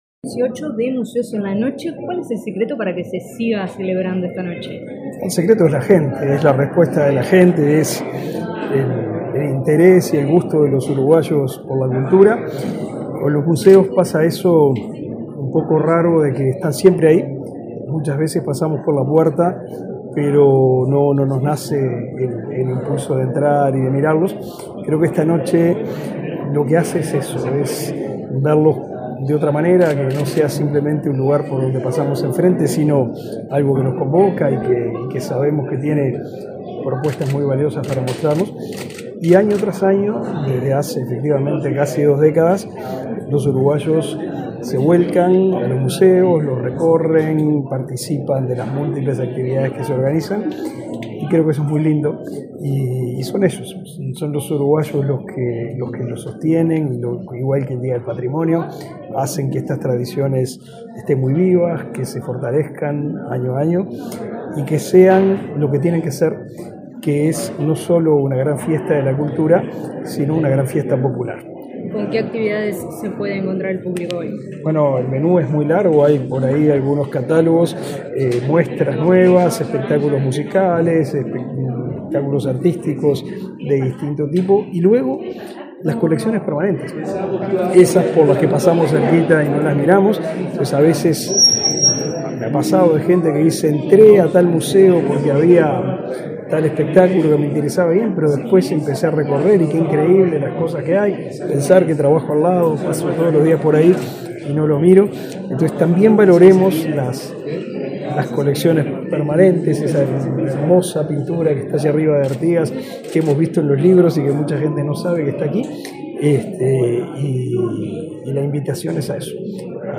Declaraciones a la prensa del ministro del MEC, Pablo da Silveira
Declaraciones a la prensa del ministro del MEC, Pablo da Silveira 08/12/2023 Compartir Facebook X Copiar enlace WhatsApp LinkedIn Tras participar en el acto de lanzamiento de la edición 2023 de Museos en la Noche, este 8 de diciembre, el titular del Ministerio de Educación y Cultura (MEC), Pablo da Silveira, realizó declaraciones a la prensa.